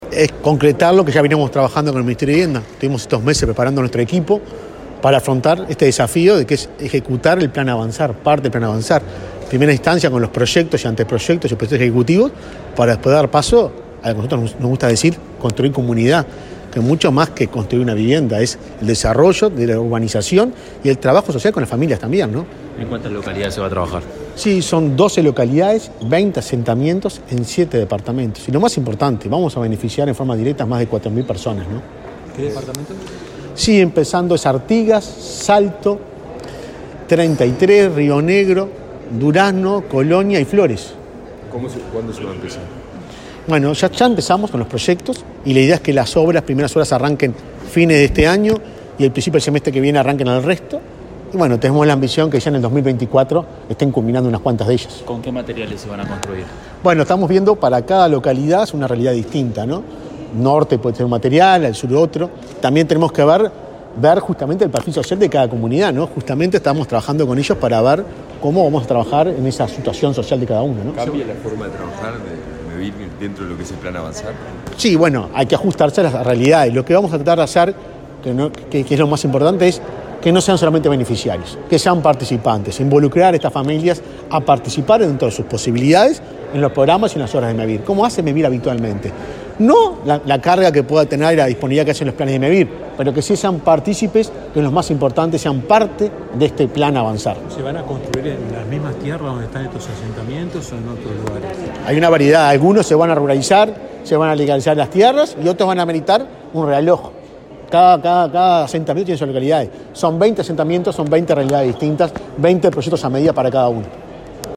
Declaraciones a la prensa del presidente de Mevir
Declaraciones a la prensa del presidente de Mevir 04/07/2022 Compartir Facebook X Copiar enlace WhatsApp LinkedIn Este lunes 4, el presidente de Mevir, Juan Pablo Delgado, dialogó con la prensa, luego de participar en la presentación de un acuerdo para elaborar proyectos del plan Avanzar.